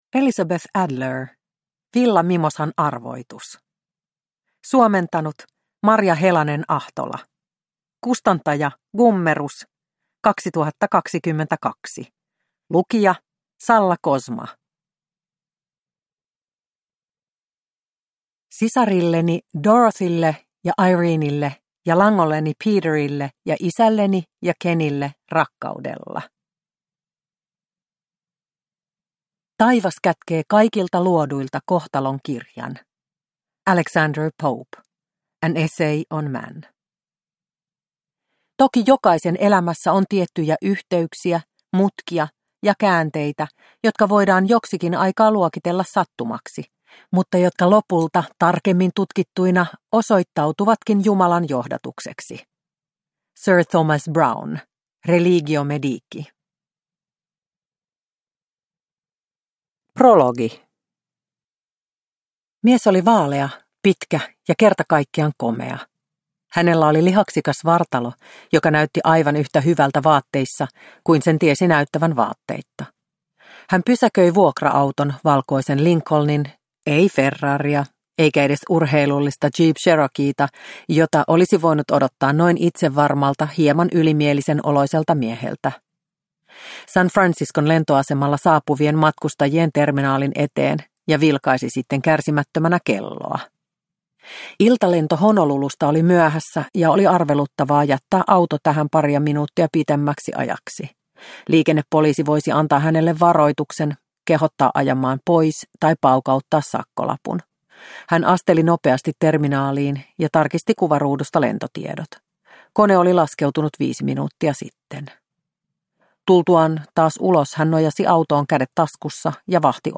Villa Mimosan arvoitus – Ljudbok – Laddas ner